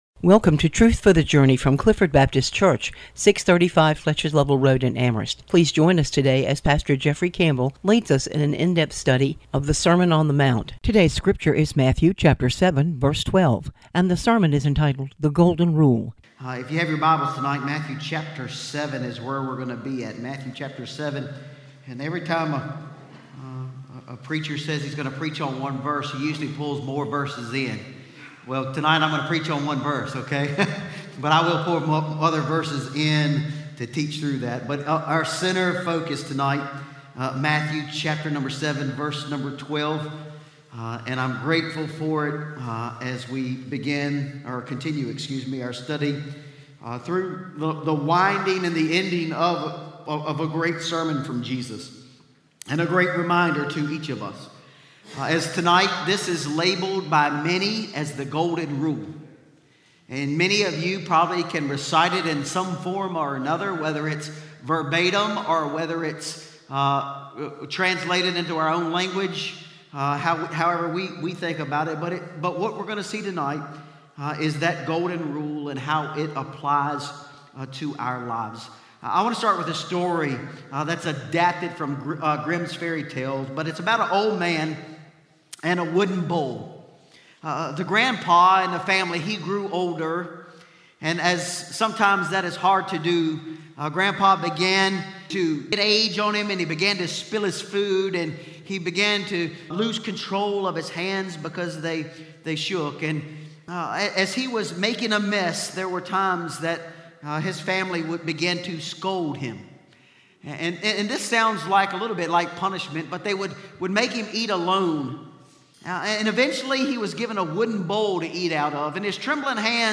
Clifford Baptist Sermon on the Mount: "The Golden Rule", Matthew 7:12 Mar 26 2026 | 00:27:12 1x 00:00 / 00:27:12 Subscribe Share Spotify RSS Feed Share Link Embed